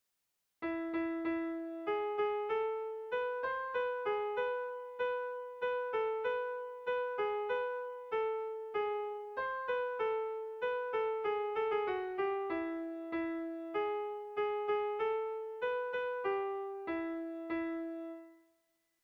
Bertso melodies - View details   To know more about this section
Sehaskakoa
AB